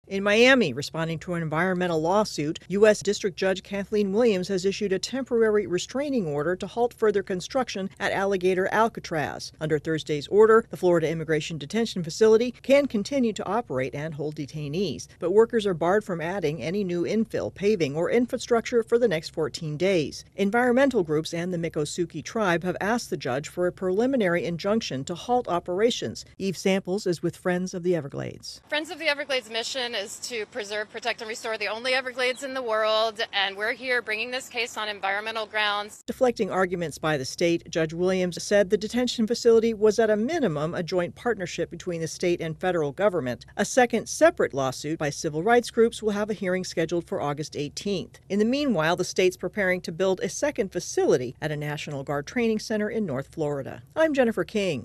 A federal judge has ordered a temporary halt to construction at the immigration detention center in the Florida Everglades. AP correspondent